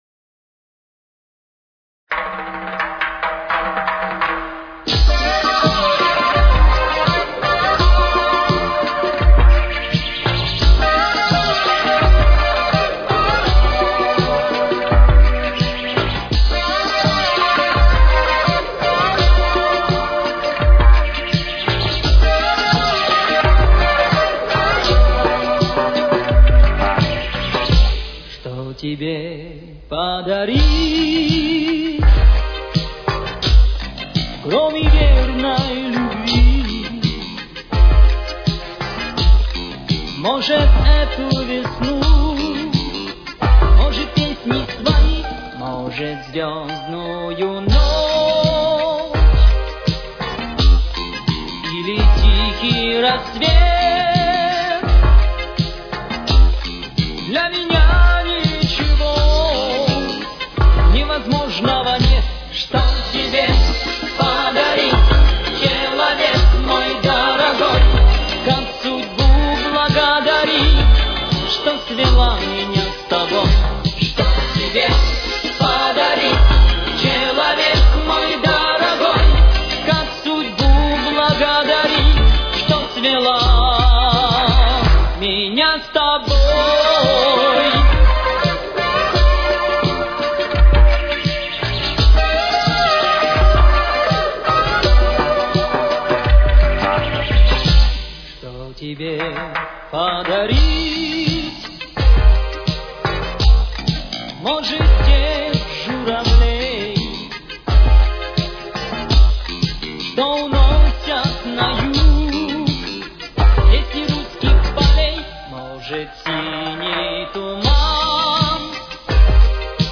с очень низким качеством (16 – 32 кБит/с)
До минор. Темп: 86.